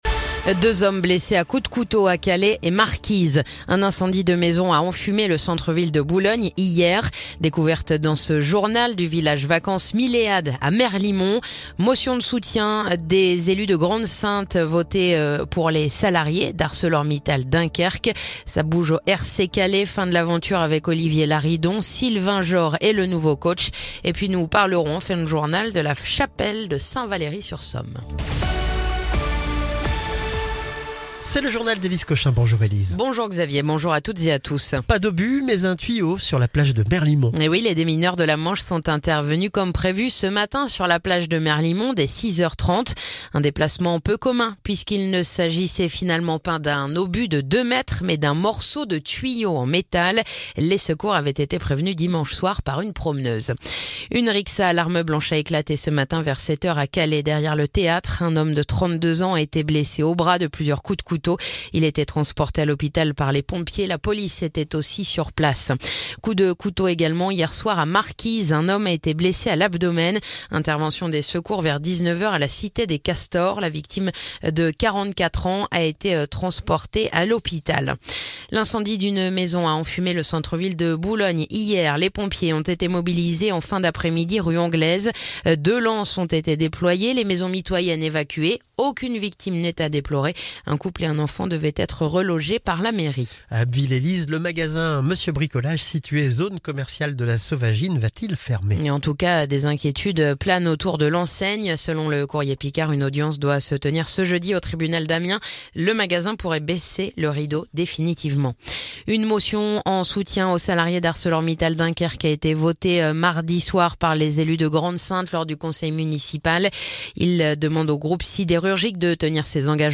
Le journal du jeudi 27 mars